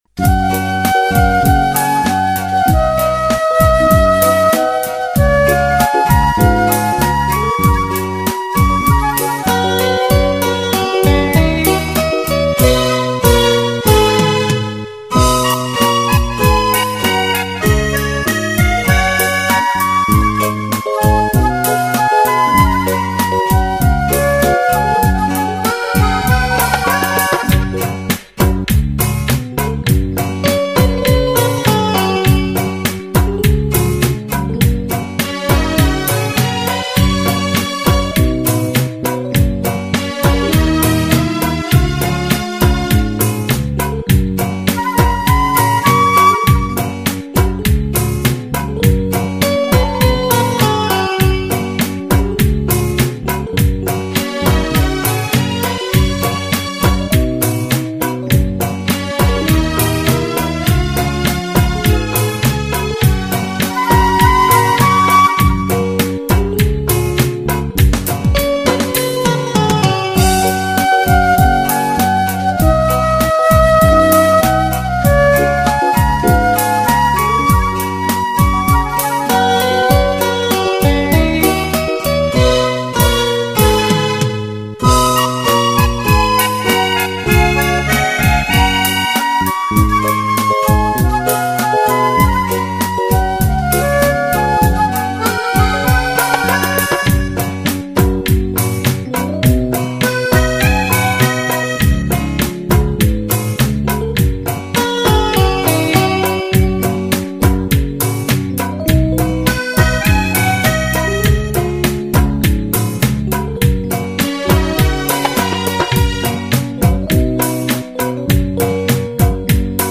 No Voice Karaoke Track Mp3 Download